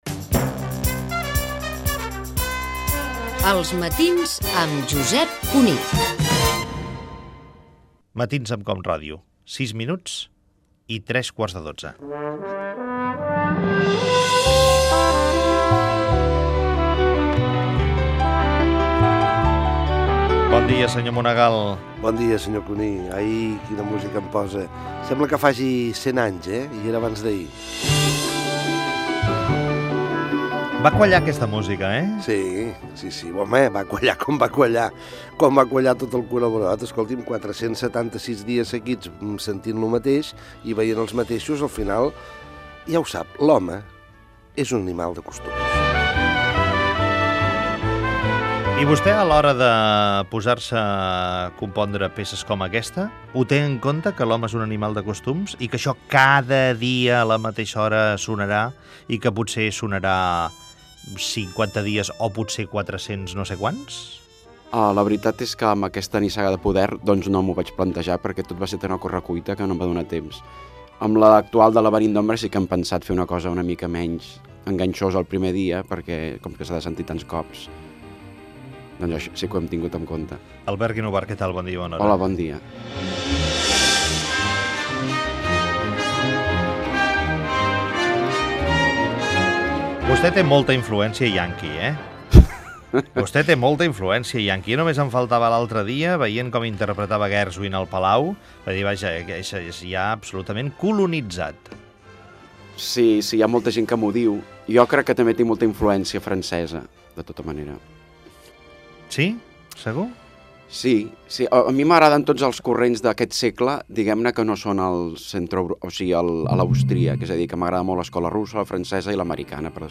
Indicatiu del programa, hora, col·laboració de Ferran Monegal, crític de televisió, i fragment d'una entrevista al compositor Albert Guinovart, autor de la sintonia del serial "Nissaga de poder" de TV3.
Info-entreteniment